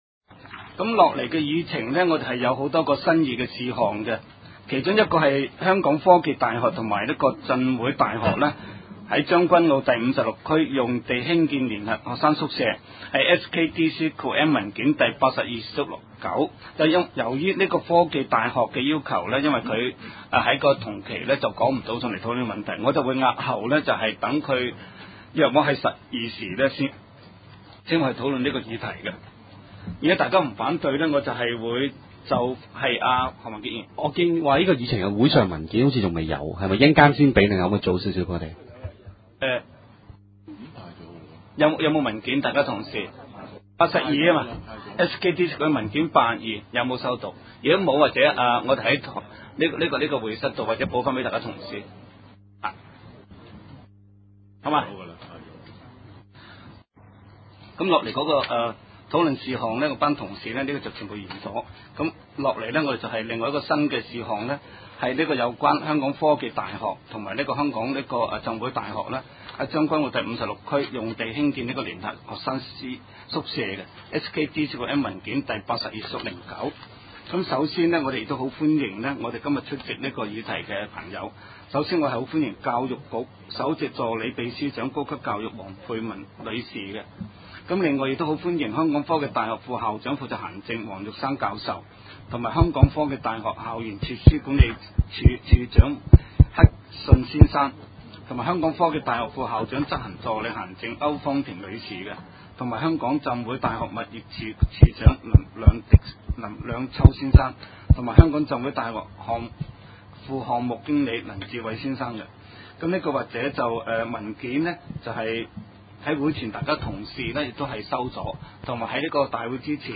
西貢區議會第 三次會議